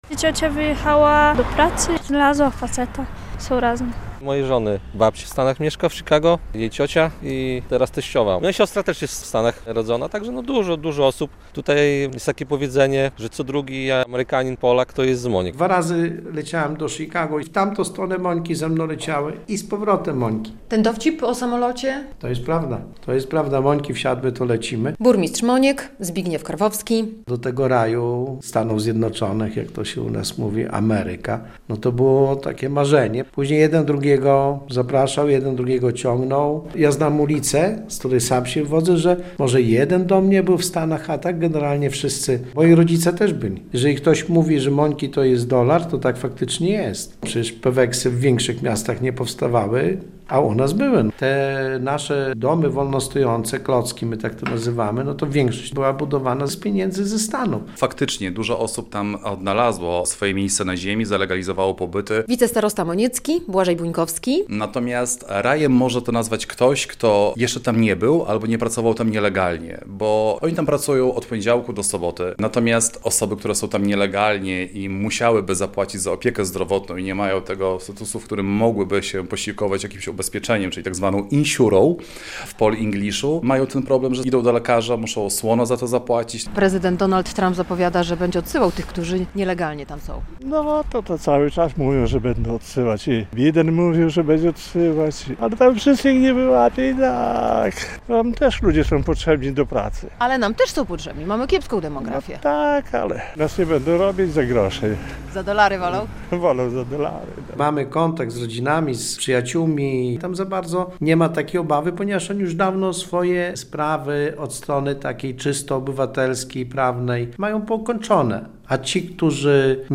Czy zapowiedzi prezydenta Donalda Trumpa mogą wpłynąć na liczbę mieszkańców Moniek? Relacja